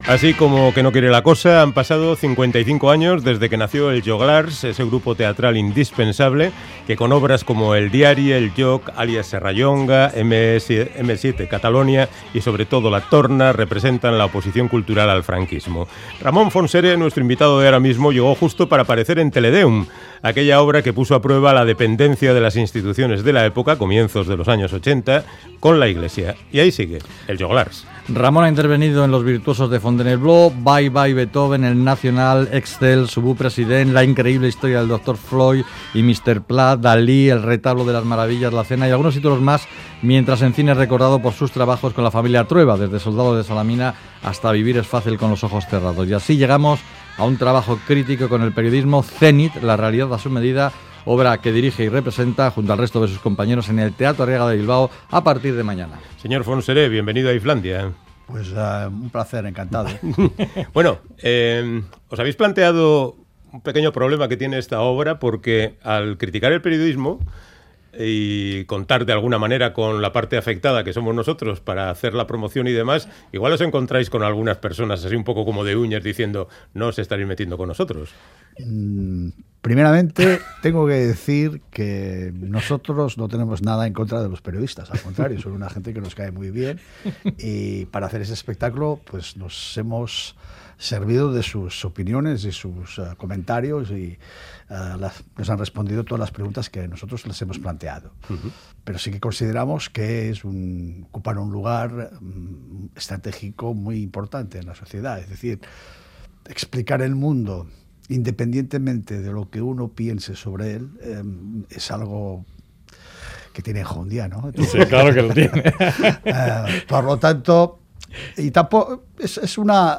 Audio: Charlamos con Ramón Fontserè, director y actor de la compañía catalana Els Joglars, sobre su último trabajo, Zenit.